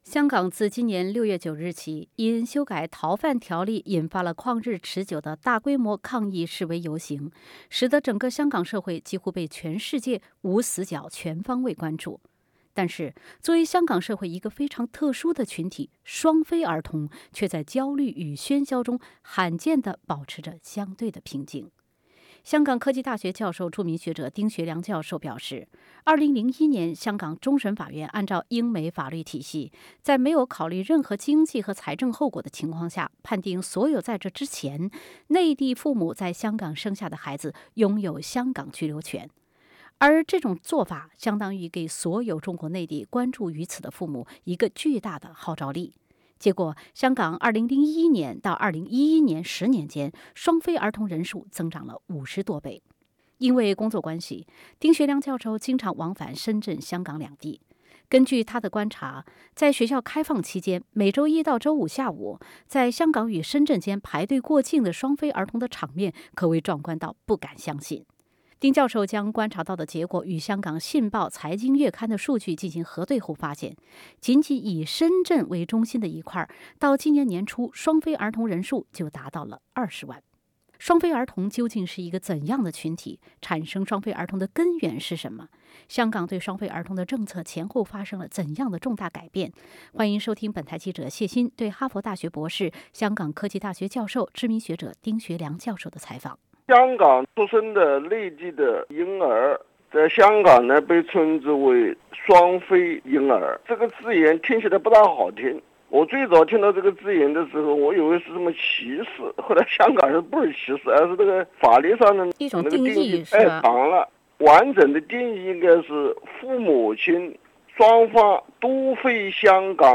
香港科技大学教授，知名学者丁学良教授的专访。